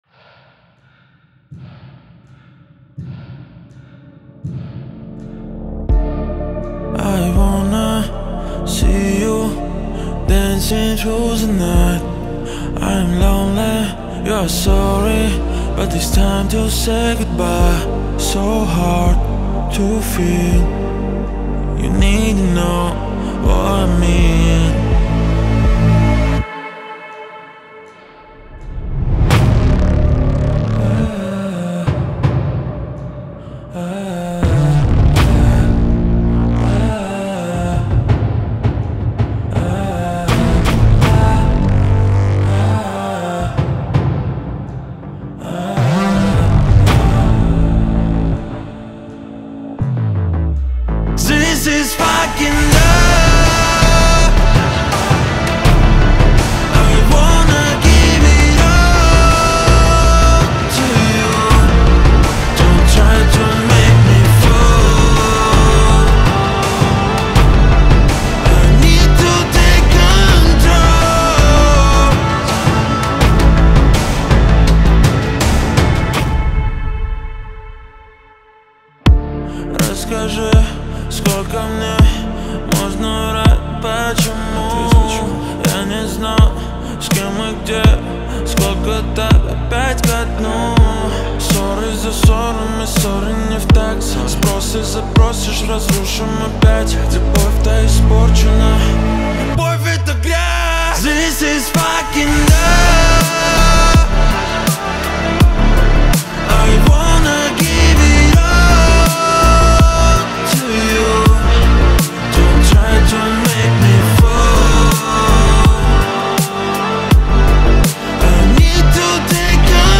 удачно сочетает элементы поп-музыки и R&B